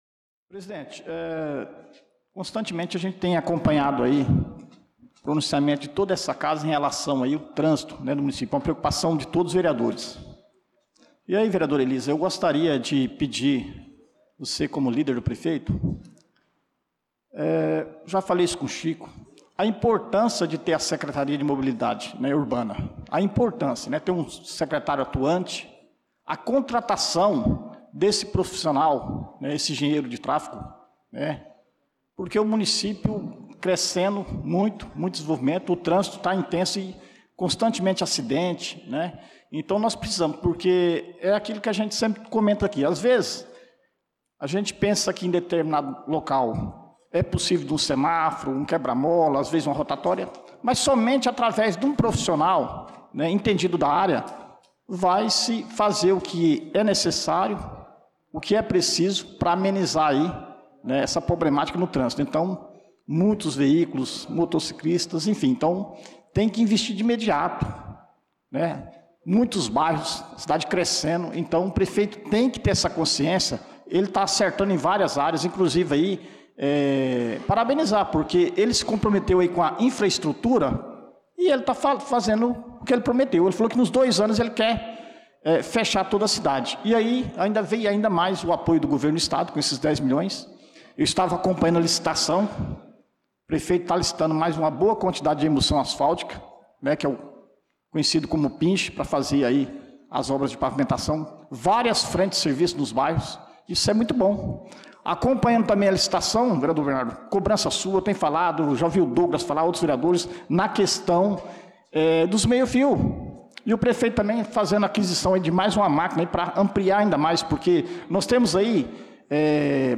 Pronunciamento do vereador Dida Pires na Sessão Ordinária do dia 18/08/2025.